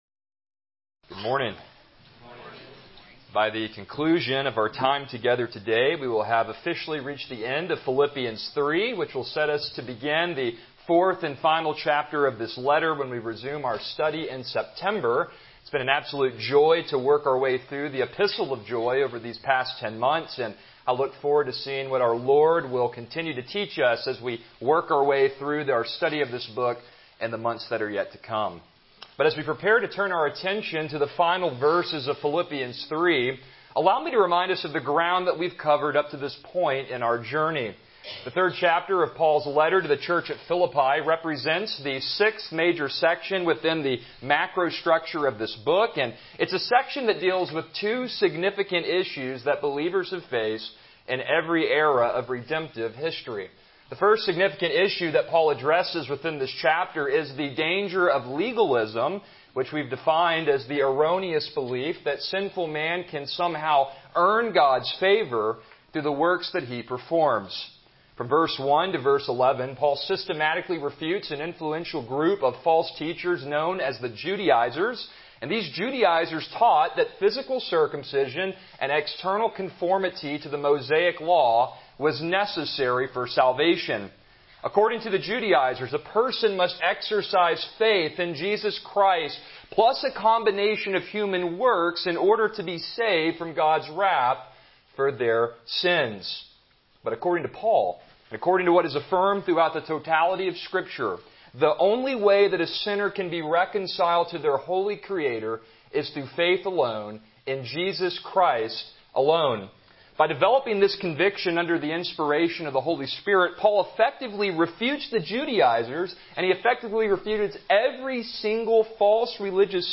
Passage: Philippians 3:20-21 Service Type: Morning Worship